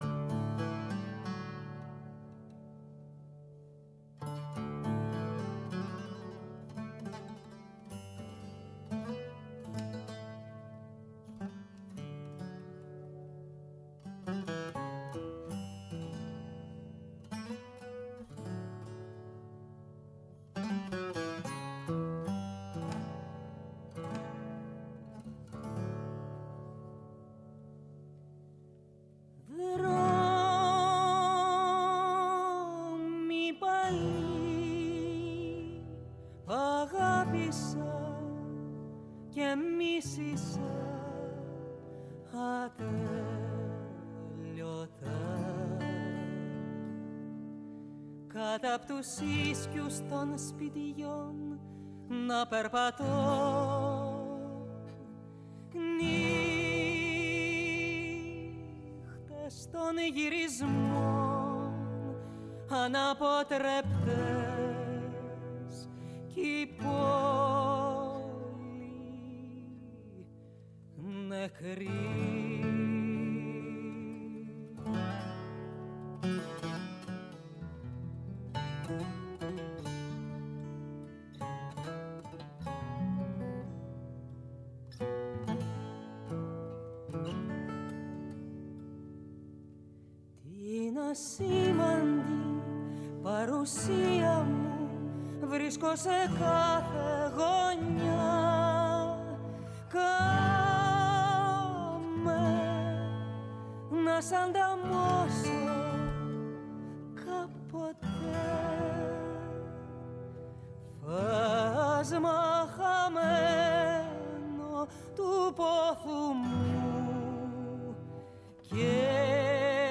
Ακούστε όλη τη συνέντευξη στο ηχητικό της ανάρτησης Η ΦΩΝΗ ΤΗΣ ΕΛΛΑΔΑΣ Κουβεντες Μακρινες ΟΜΟΓΕΝΕΙΑ